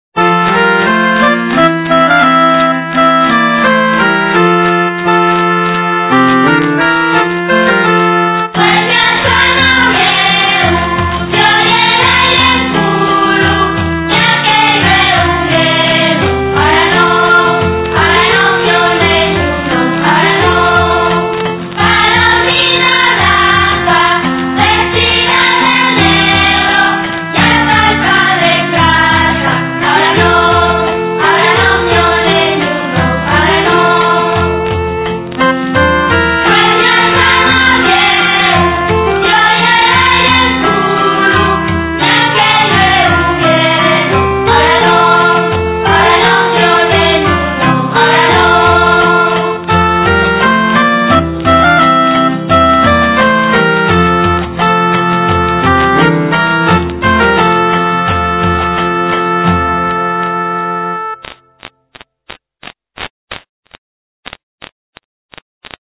De xuru,que la lletra conozla fasta la vuesa güela porque son cancios de la tradición musical asturiana recoyíos munchos d'ellos del cancioneru musical d'Eduardo Martínez Torner.
Los que canten son los neños de los colexos públicos de Lieres y Sariegu y pa que-yos salga tan bien tienen qu'ensayar dos hores a la selmana